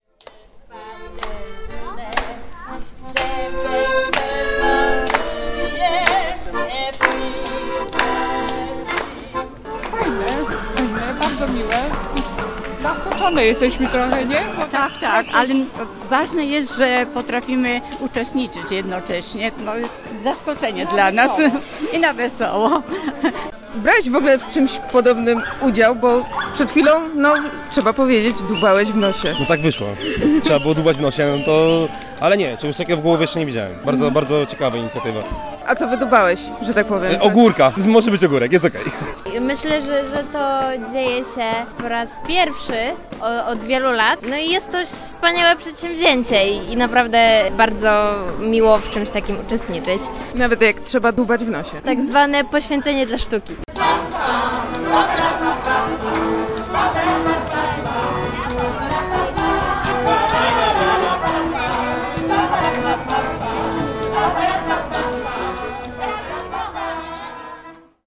Parada Zmysłów w Głogowie
Aktorzy przebrani za różne części ciała zachęcali do wspólnej zabawy, zaczepiali mieszkańców i maszerowali przy akompaniamencie akordeonu.
- Fajne, bardzo miłe. Jesteśmy zaskoczone trochę, ale ważne jest, że potrafimy w tym uczestniczyć - mówią dwie uczestniczki parady.